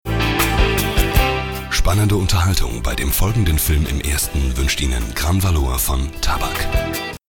deutscher Sprecher und Sänger mit variantenreicher Stimme.
Sprechprobe: Werbung (Muttersprache):